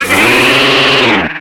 Cri de Chevroum dans Pokémon X et Y.